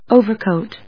音節o・ver・coat 発音記号・読み方
/óʊvɚkòʊt(米国英語), ˈəʊvək`əʊt(英国英語)/